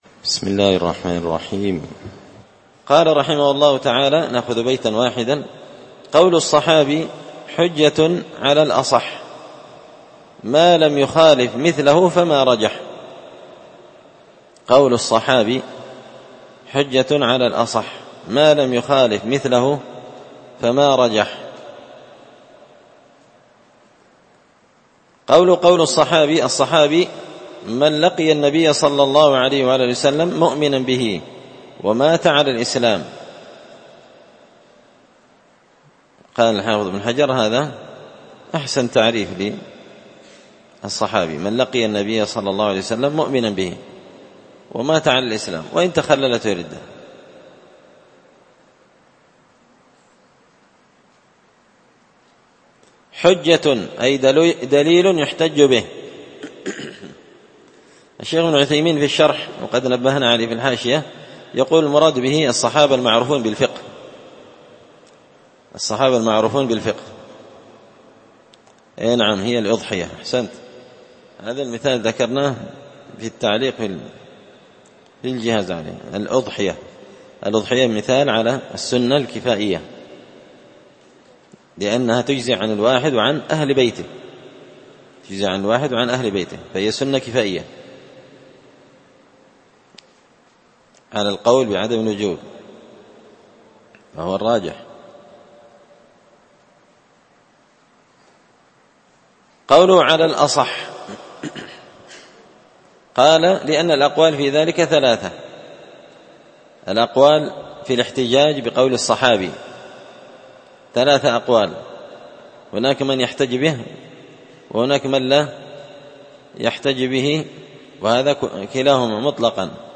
تسهيل الوصول إلى فهم منظومة القواعد والأصول ـ الدرس 26